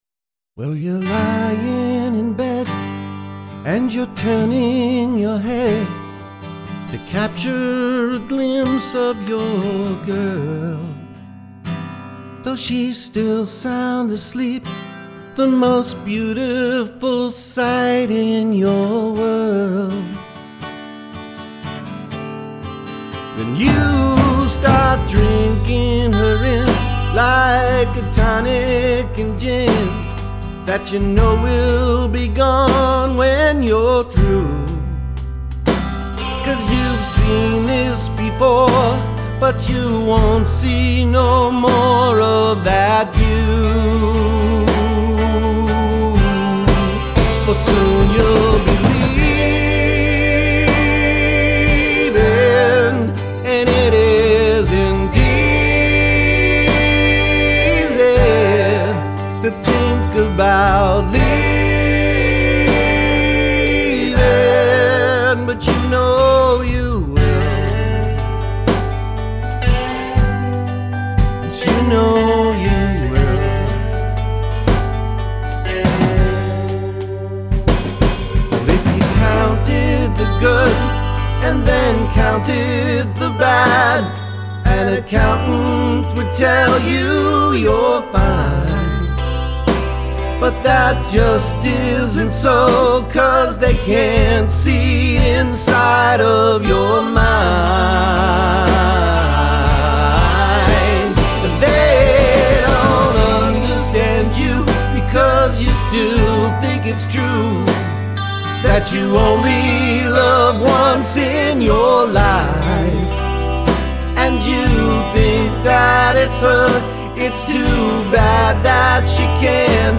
I don't know what genre to put this one in -- it reminds me sort of country ... sort of Eagles, maybe?